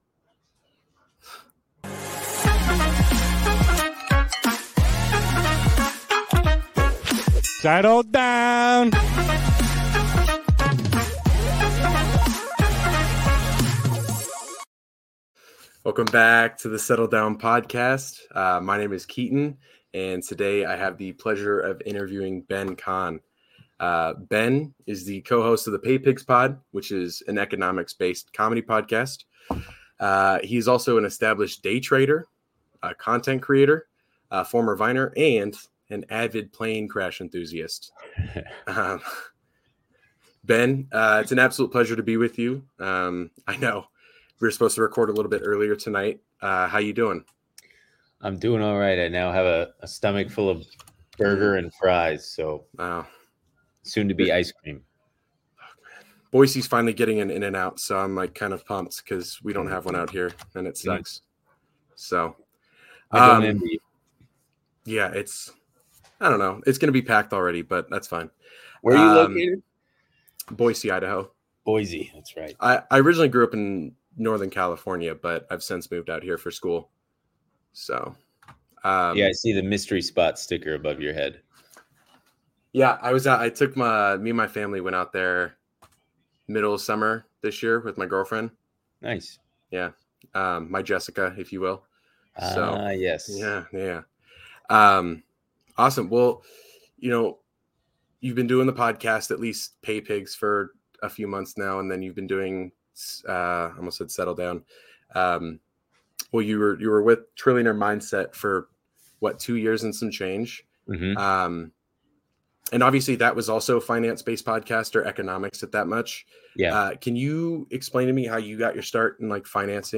Settle Down Podcast Interview